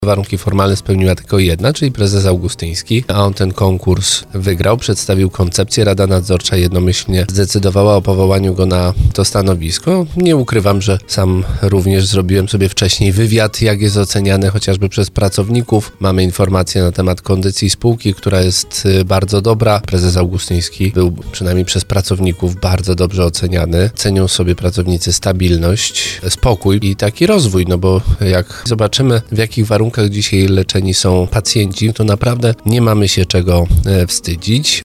Jak tłumaczył w rozmowie Słowo za Słowo prezydent Tarnowa Jakub Kwaśny, zmiana wynikła ze złego stanu zdrowia dotychczasowego prezesa.